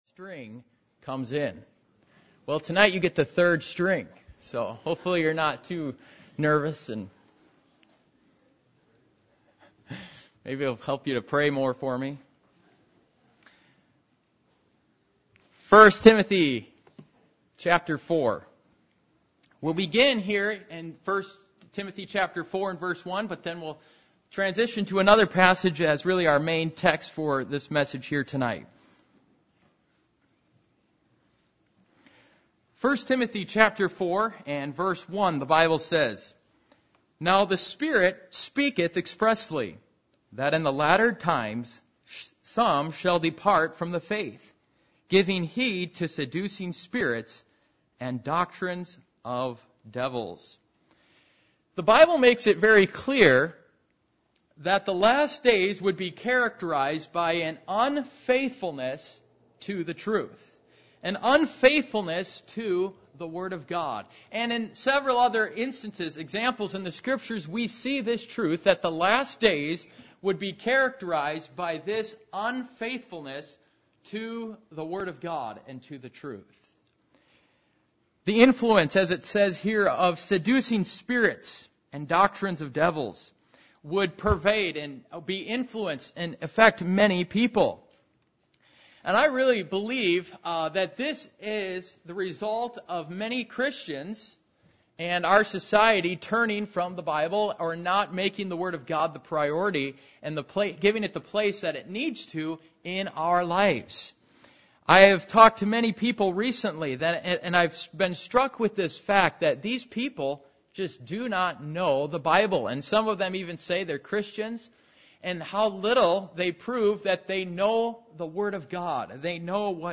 Passage: 1 Thessalonians 4:1, James 5:8, 1 Timothy 6:20 Service Type: Midweek Meeting %todo_render% « Challenge to the Church